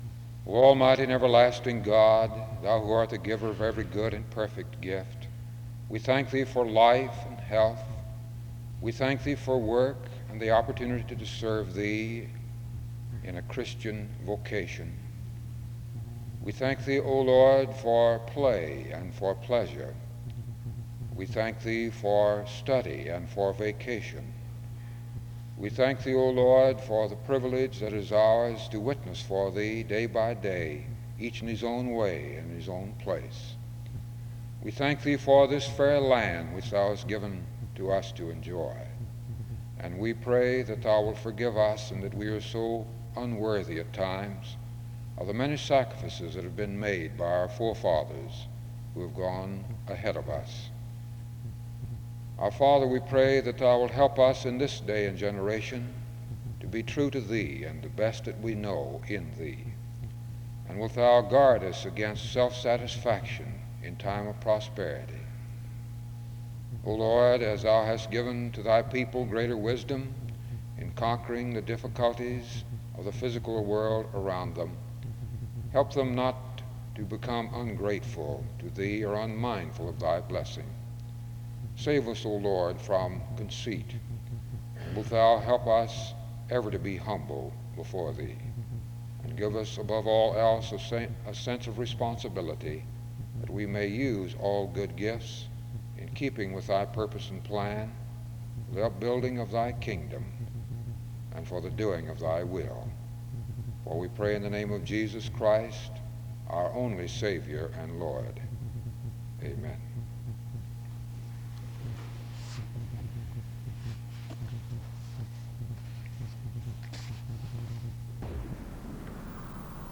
SEBTS Chapel
SEBTS Chapel and Special Event Recordings SEBTS Chapel and Special Event Recordings